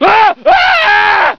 scream22.ogg